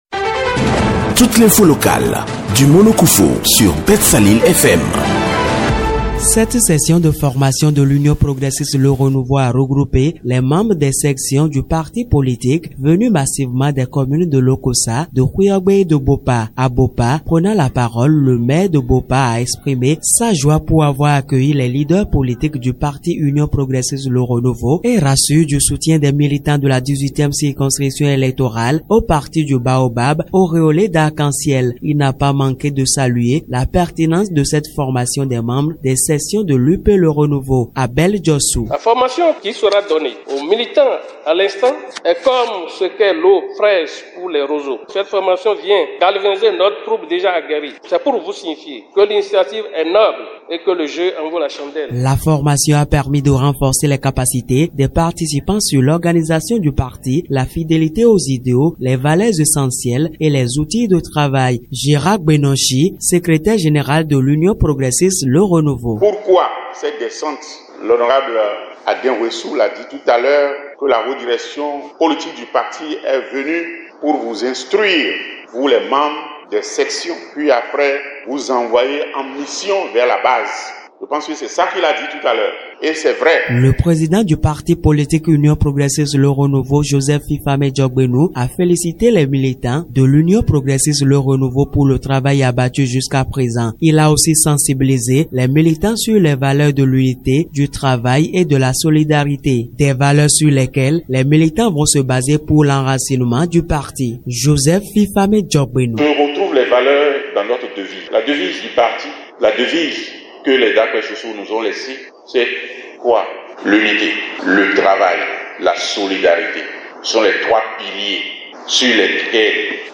Ce mercredi 17 juillet 2023, la haute direction de l’UP le renouveau a posé ses valises dans la 18e circonscription électorale précisément au centre des jeunes et loisirs de Bopa. L’objectif poursuivi par les responsables du parti Baobab auréolé d’arc-en-ciel est de renforcer les capacités des membres des bureaux des sessions des communes de Bopa, de Houéyogbé et de Lokossa qui composent la 18e circonscription électorale.
REPORTAGE-UP-LE-RENOUVEAU.mp3